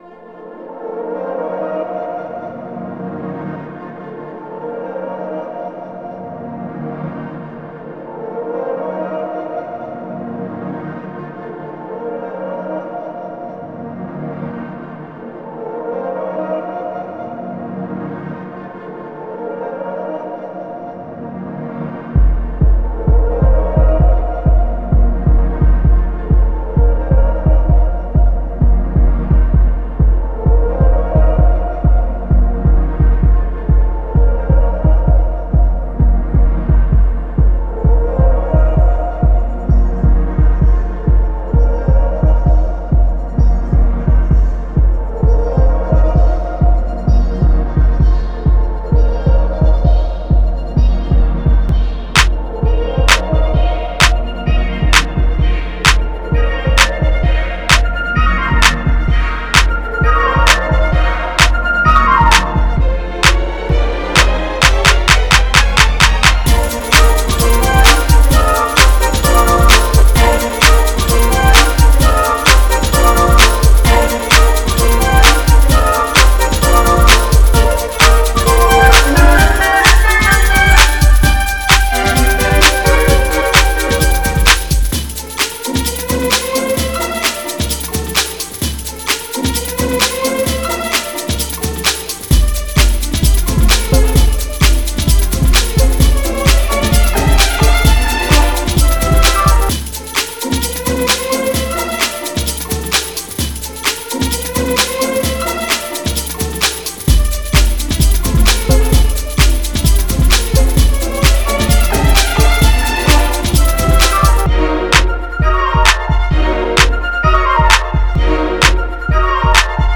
at 130 b.p.m.
baltimore club